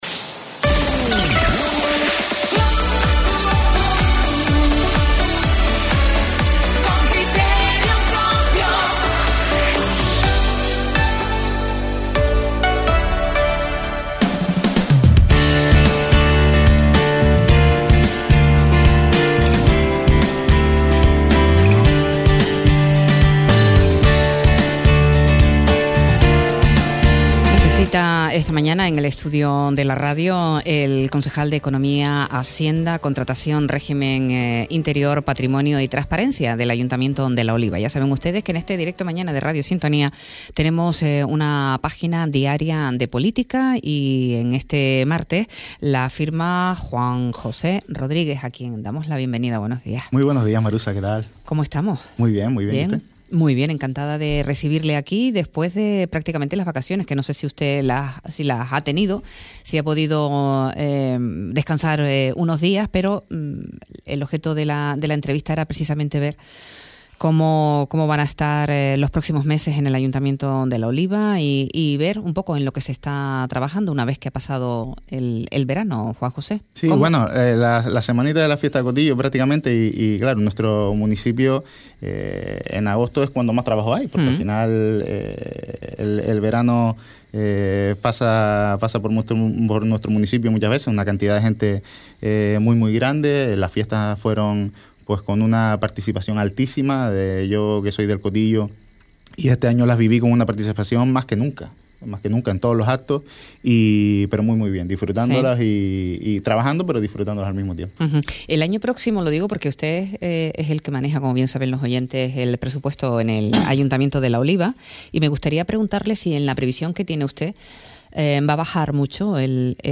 Entrevista a Juan José Rodríguez, concejal de Economía y Hacienda en La Oliva - 24.09.24 - Radio Sintonía